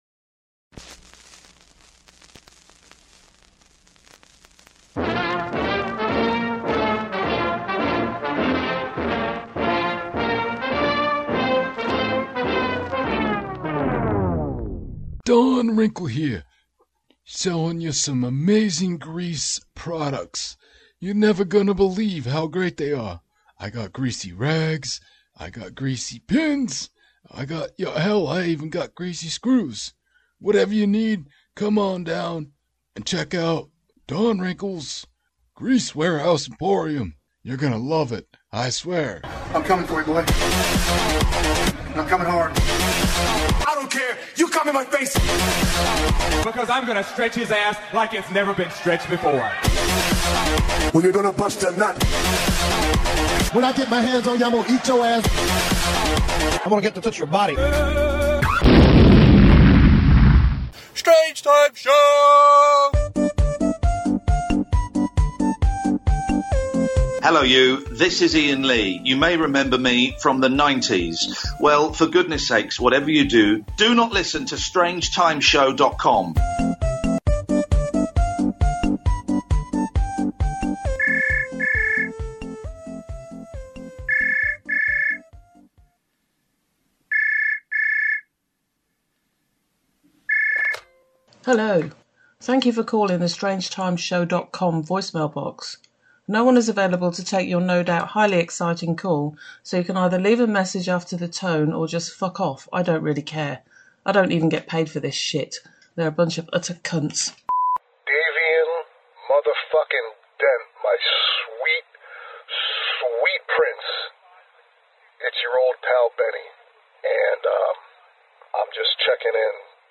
A dark comedy, improvisational talk show